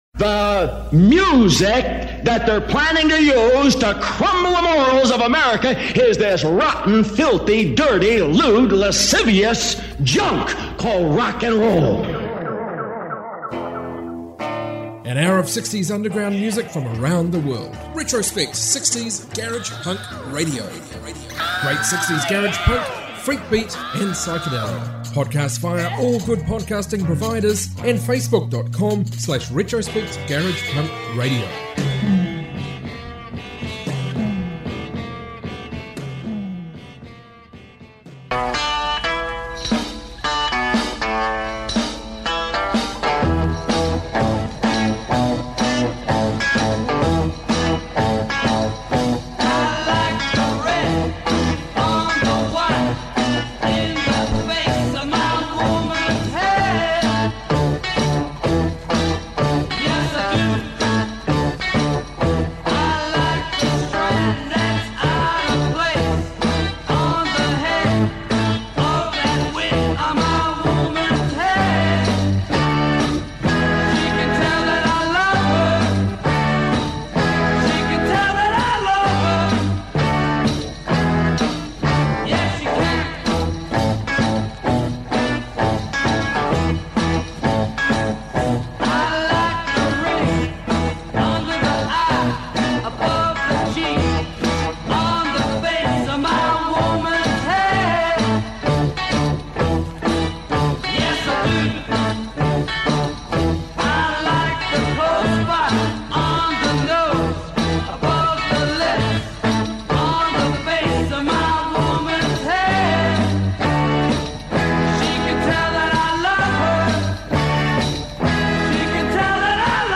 60s garage punk, garage rock, freakbeat from around the globe